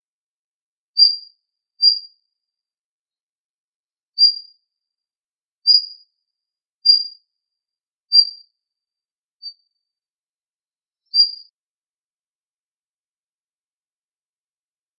ジョウビタキの鳴き声 「ヒッ、ヒッ」 着信音